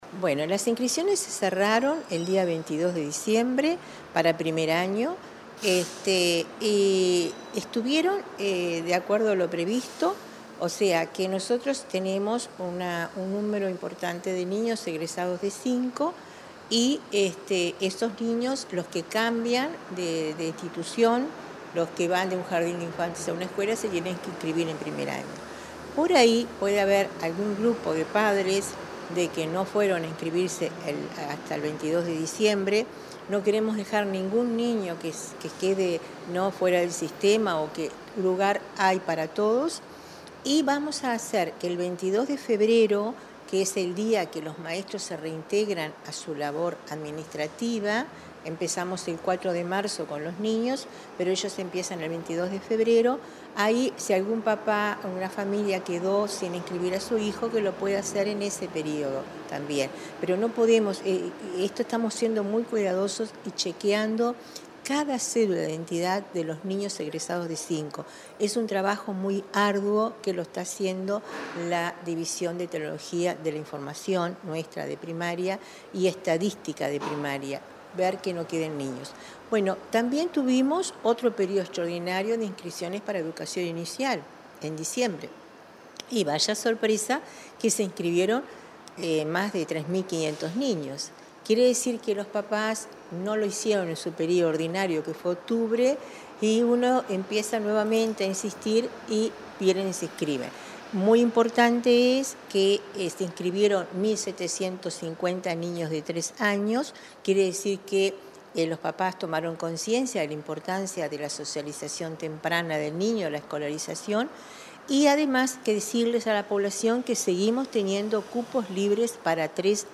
Entrevista a la directora de Educación Inicial y Primaria, Olga de las Heras